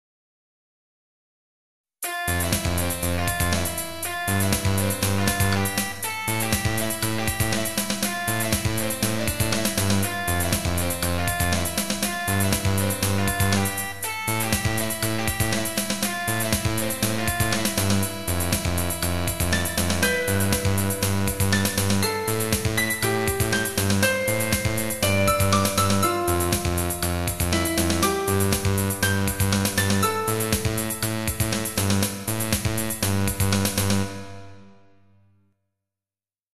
昨日のやつのオケのみ、音源ちょっと変えてみた。
オーバードライブギターのみのＭＩＤＩファイルを作り、ＹＡＭＡＨＡの音源で録ってあとで合わせました。
ＹＡＭＡＨＡ音源はパーカッションがあまり好きでない。ハイハットはもうちょっといい音出してくれ。